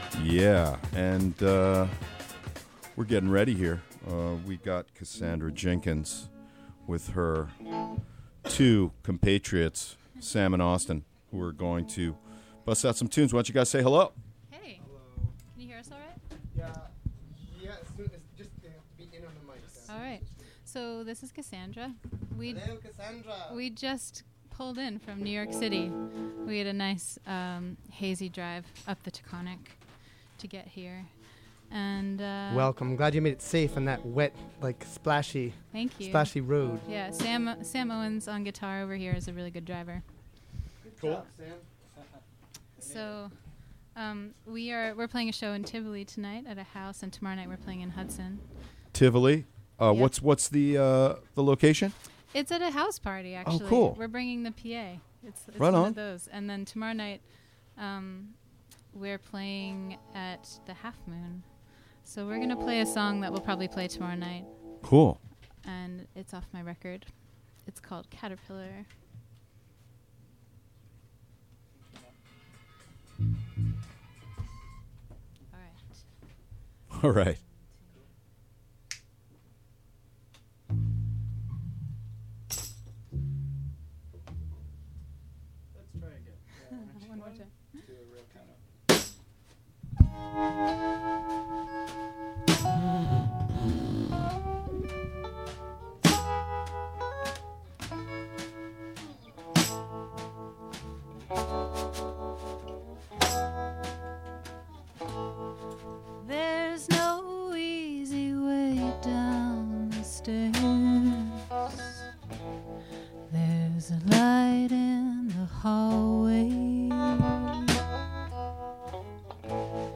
Cassandra Jenkins talks about something.